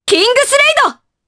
Naila-Vox_Kingsraid_jp.wav